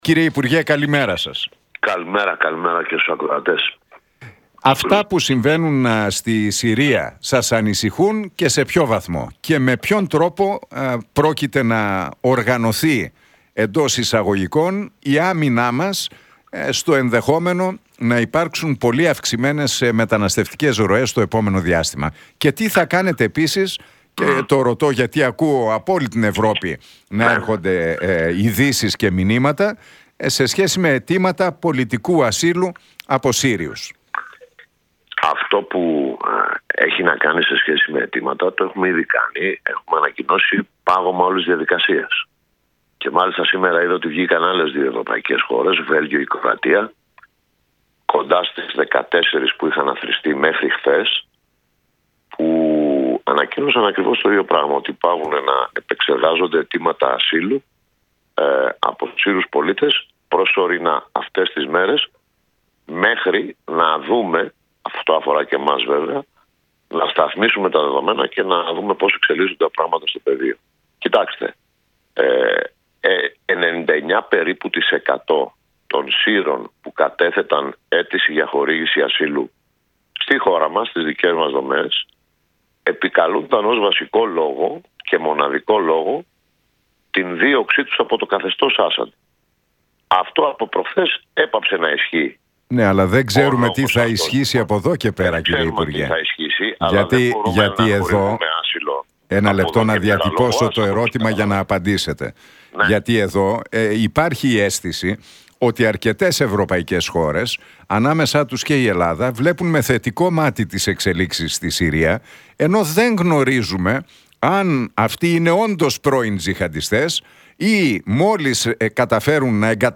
Για τις εξελίξεις στην Συρία και τις προσφυγικές ροές μίλησε ο υπουργός Μετανάστευσης και Ασύλου, Νίκος Παναγιωτόπουλος στον Realfm 97,8 και την εκπομπή του Νίκου Χατζηνικολάου.